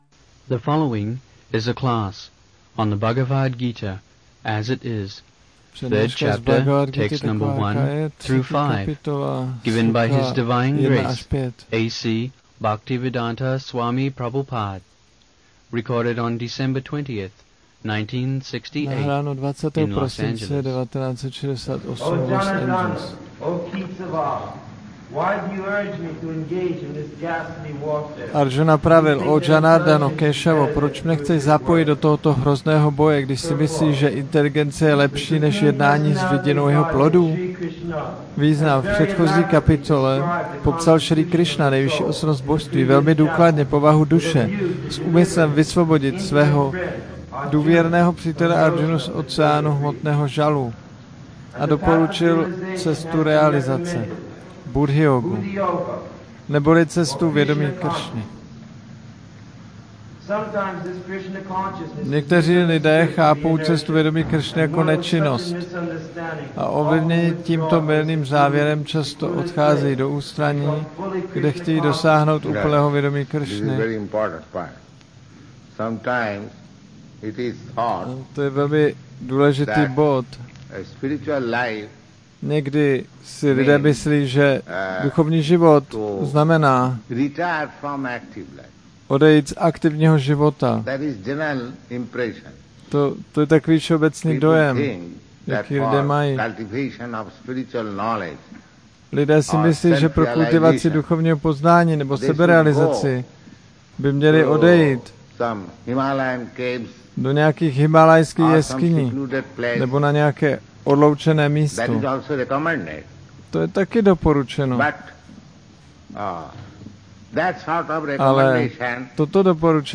1968-12-20-ACPP Šríla Prabhupáda – Přednáška BG-3.1-5 Angeles